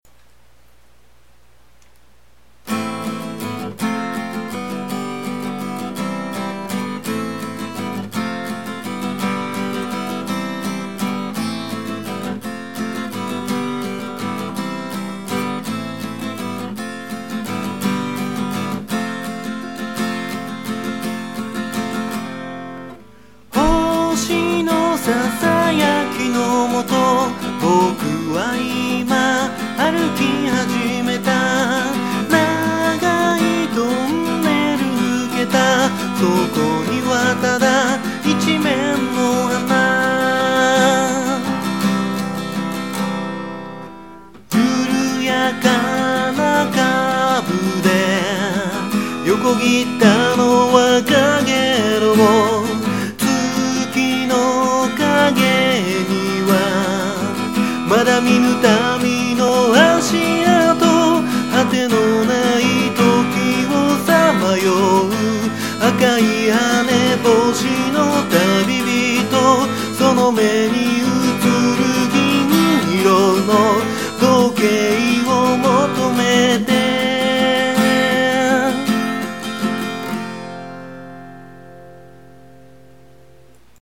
録音環境が整ってないので音割れしまくるのは仕様！！